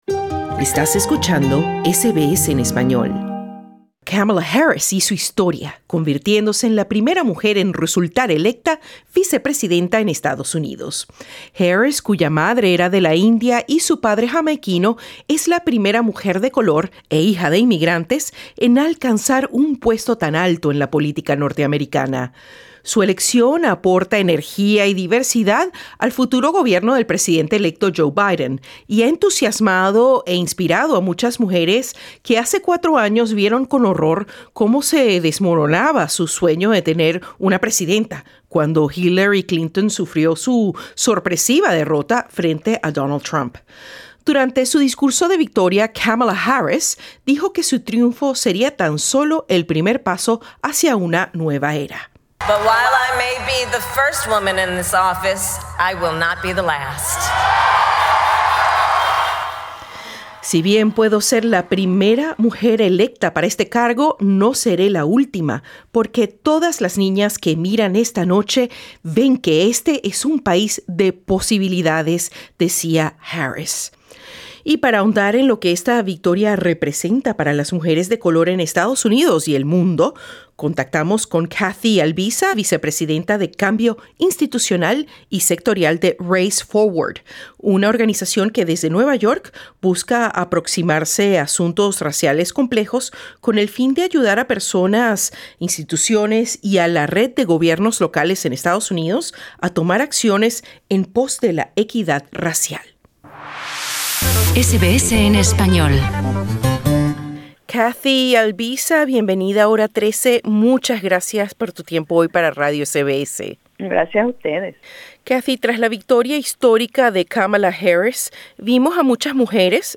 En entrevista con SBS Spanish